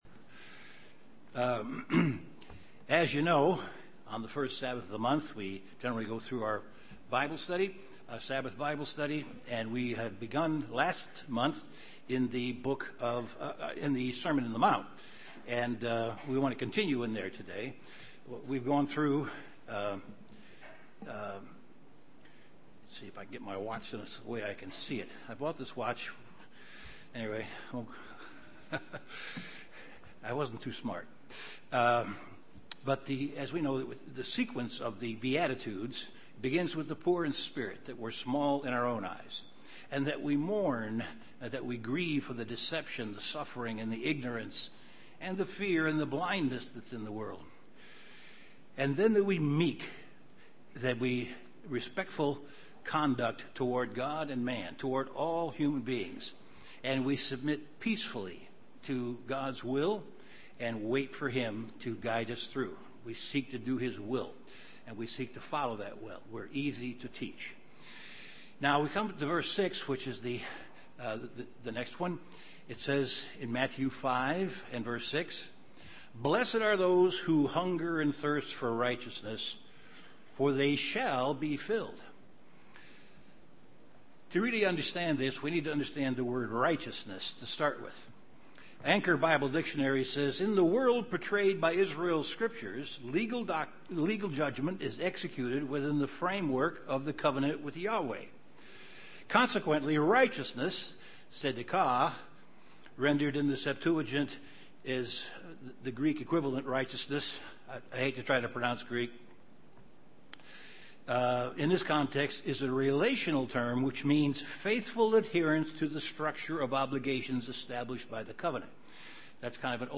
The second session of an in-depth Bible Study on the Sermon on the Mount. This study discusses the Beatitudes.
Given in Chicago, IL
UCG Sermon Studying the bible?